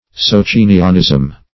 Socinianism \So*cin"i*an*ism\, n. (Eccl. Hist.)